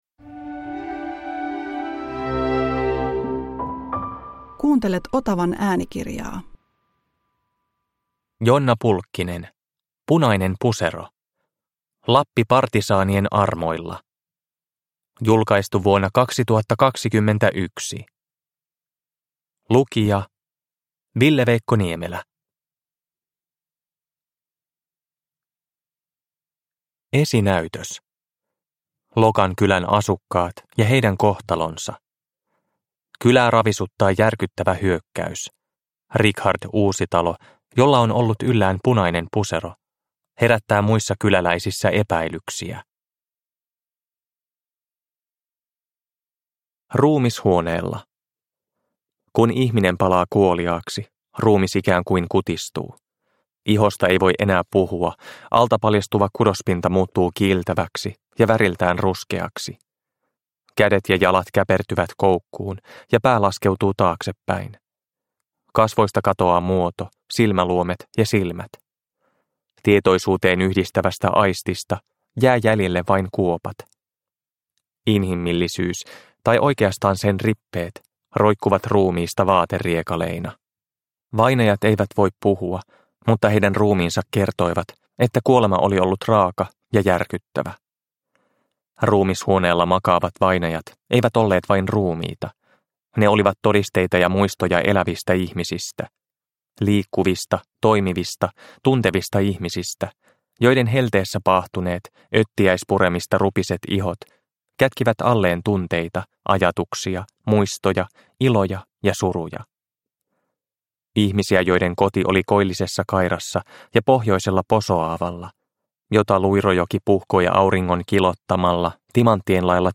Punainen pusero – Ljudbok – Laddas ner